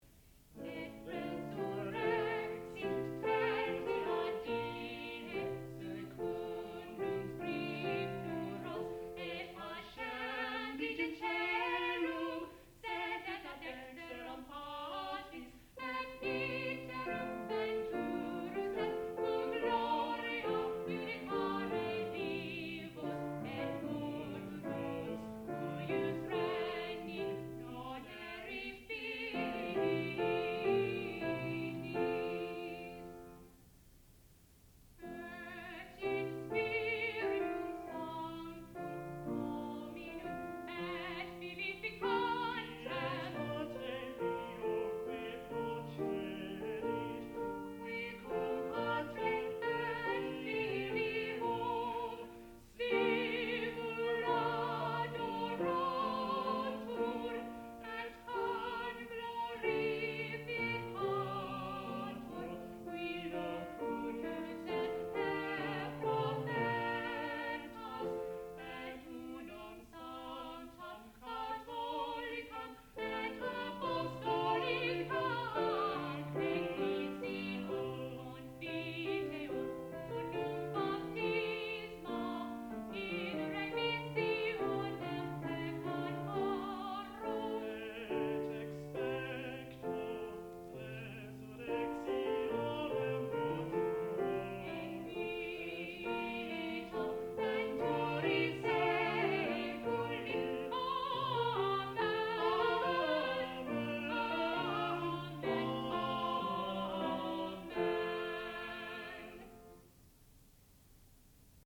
sound recording-musical
classical music
piano
tenor